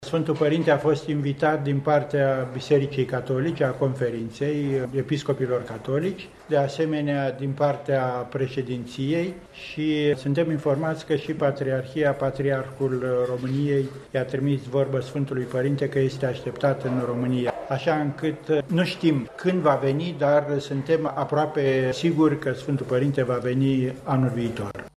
Suveranul Pontif a primit invitaţii oficiale şi din partea Bisericii Ortodoxe Române, după cele venite de la reprezentanţii statului, iar vizita Papei Francisc este aproape o certitudine, a spus la Cluj Napoca mitropolitul romano-catolic Ioan Robu:
Declaraţia a fost făcută la finalul Conferinţei Episcopilor Catolici din România.